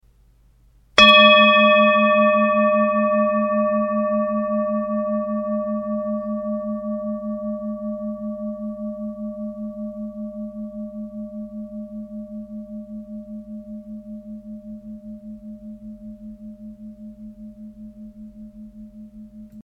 Tibetische Klangschale UNIVERSALSCHALE 1047g KM67
Durchmesser: 19,5 cm
Grundton: 215,31 Hz
1. Oberton: 608,14 Hz